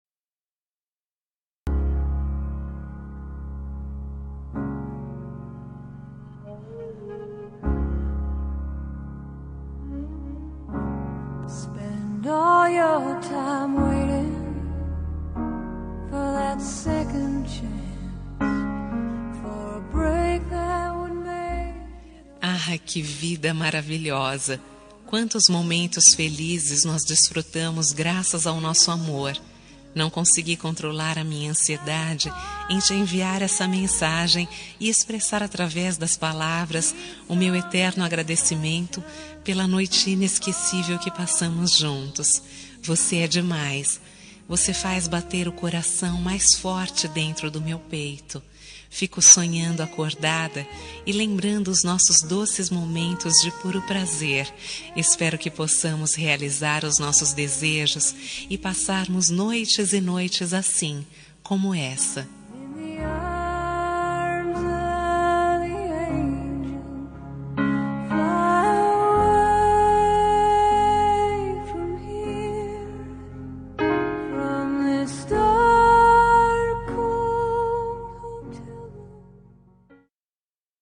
Telemensagem de Agradecimento – Romântica – Voz Feminina – Cód: 21
Romantica -fem 2031.mp3